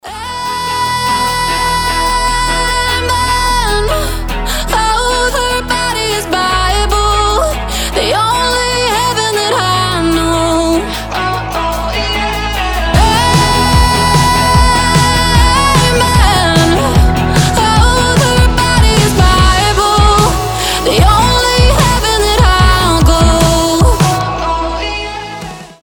alternative
new wave